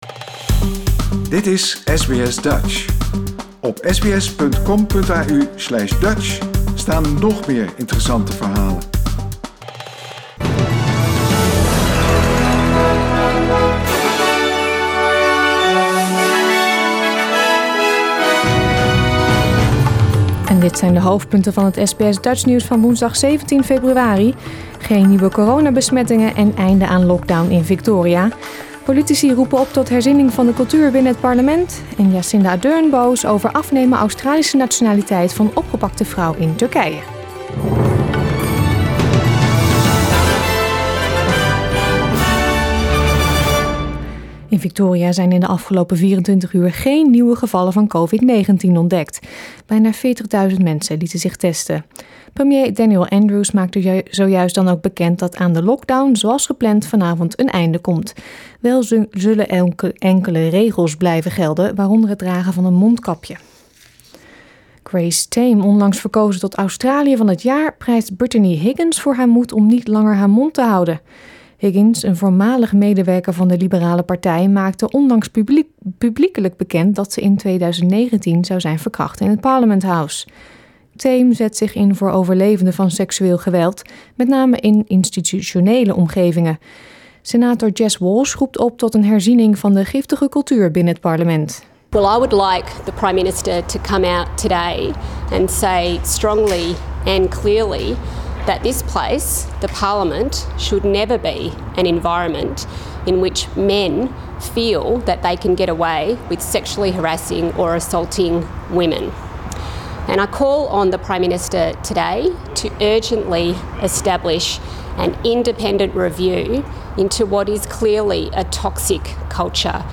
Nederlands/Australisch SBS Dutch nieuwsbulletin woensdag 17 februari 2021